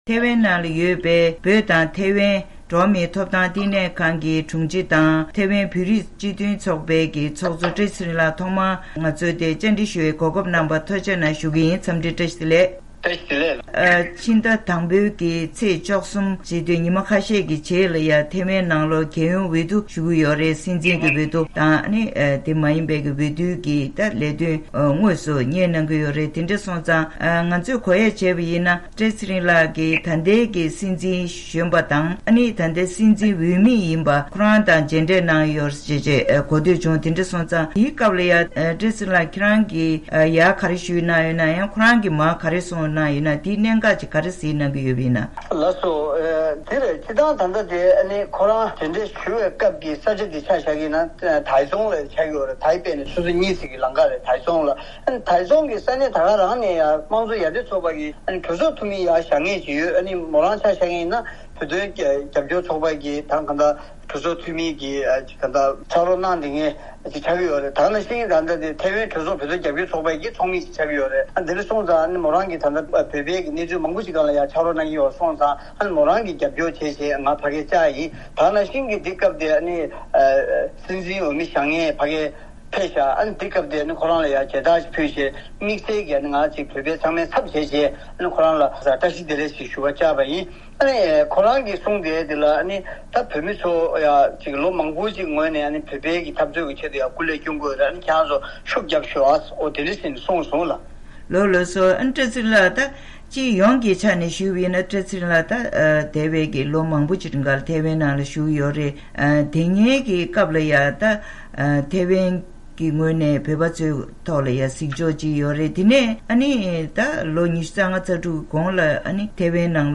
དམིགས་བསལ་གནས་འདྲིའི་ལེ་ཚན་ནང་།